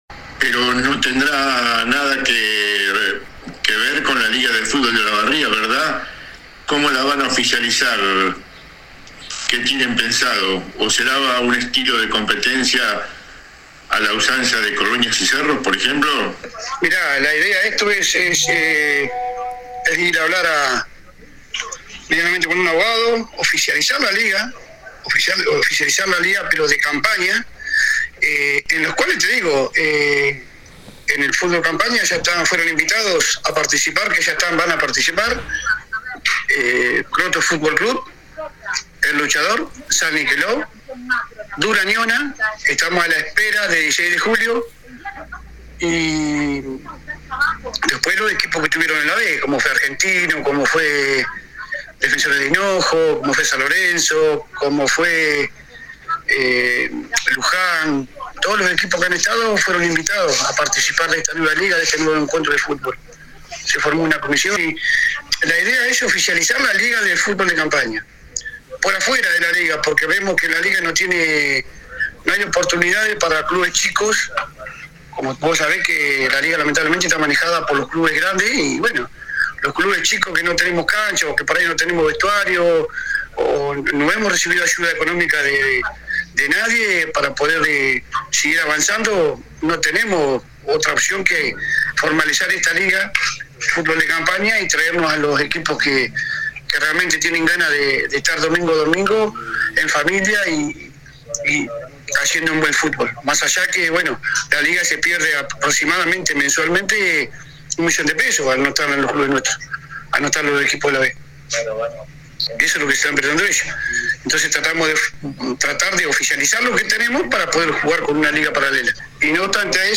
AUDIO DE LA ENTREVISTA ( EN DOS BLOQUES )